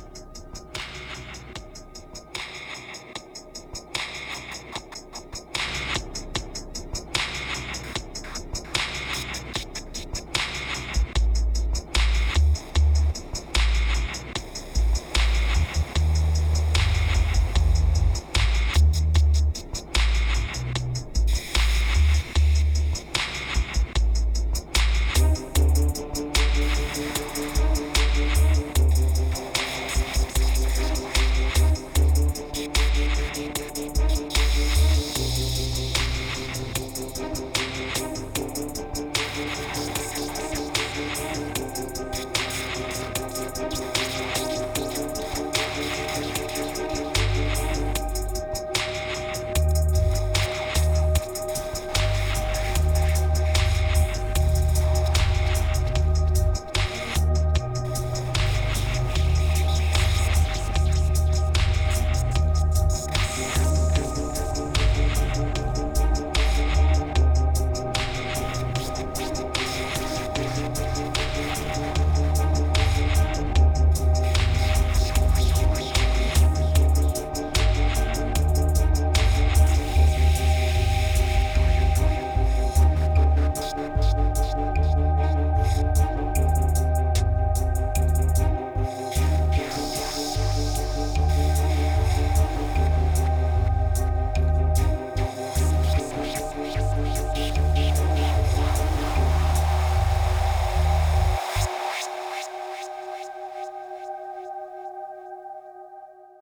2628📈 - 43%🤔 - 75BPM🔊 - 2017-09-09📅 - 65🌟